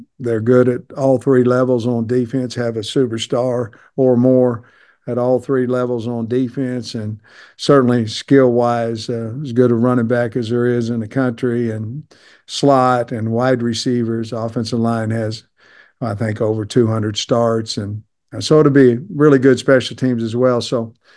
Pittman discusses his scouting of OSU and the talent of Gordon: